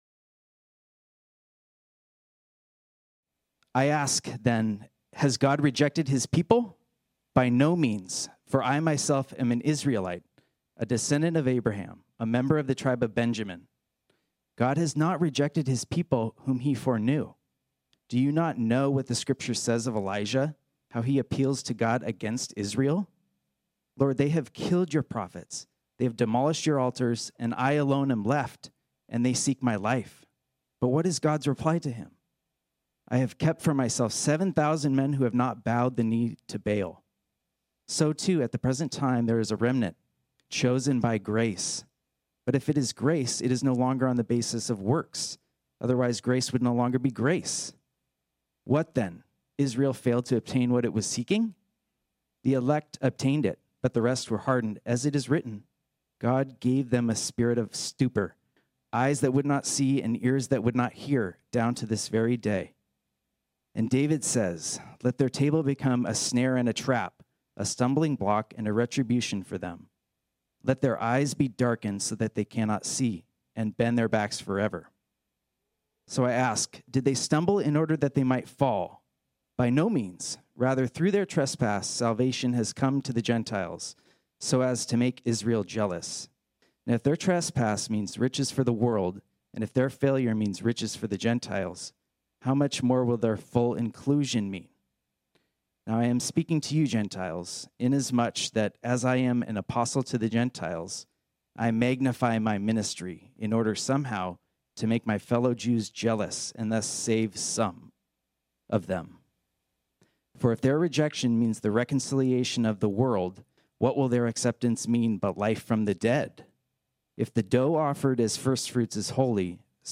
This sermon was originally preached on Sunday, June 13, 2021.